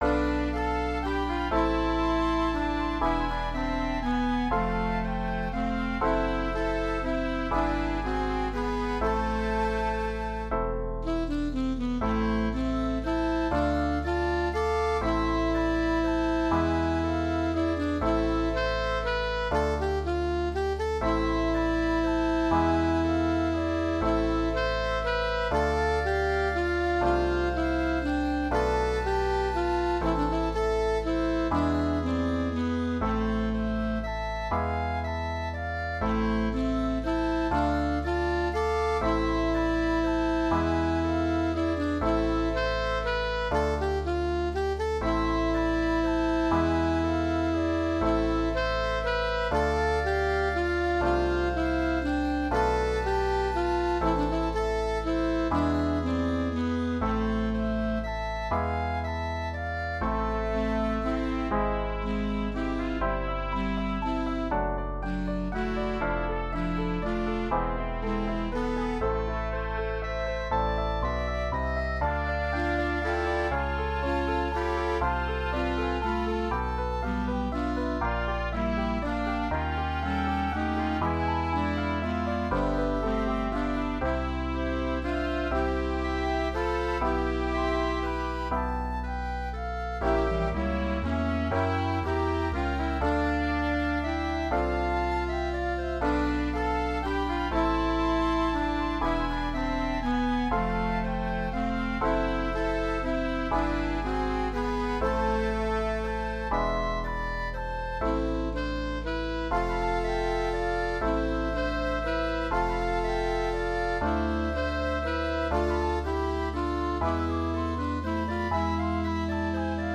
לחליל, קלרינט, שני סקסופונים, חצוצרה ופסנתר.
הרמוניה טובה. אהבתי במיוחד את המהלכים הכורמטיים..
נעים לשמוע ומאד רגוע תצליח